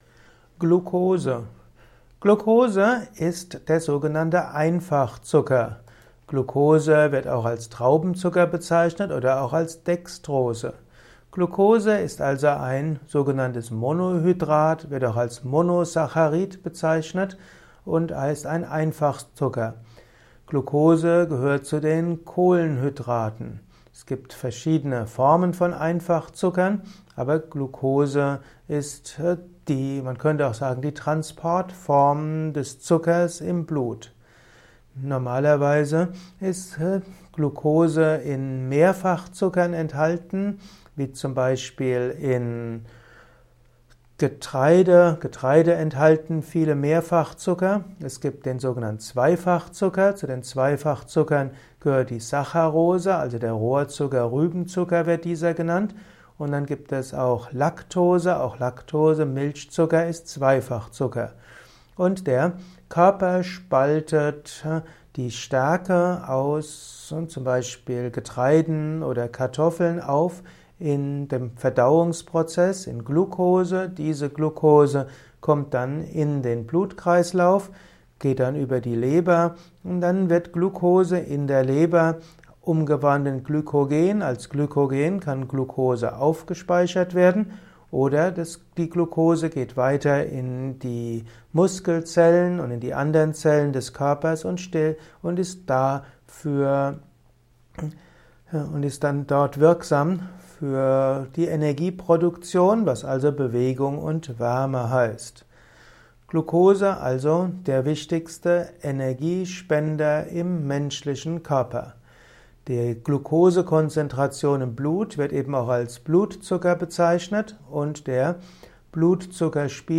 Ein Kurzvortrag über Glukose